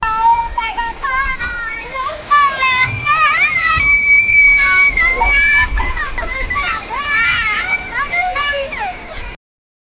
Start of story, livelier and dramatic 0:10 A group of joyful kids riding their bicycles on a street, with clear ‘dring dring’ bicycle bell sounds as they arrive. Light laughter and wheel movement ambience. In the background, soft distant thunder rumbling (‘gar-garad’) from cloudy weather, adding excitement without overpowering the kids’ sounds. Bright, playful, and lively environment with a mix of fun and slight dramatic thunder atmosphere. 0:10 the dog is barking 0:15 A lively African gospel congregation shouting ‘AMEN!’ full of passion, warmth, and loud energy.
a-group-of-joyful-kids-jrd5spoi.wav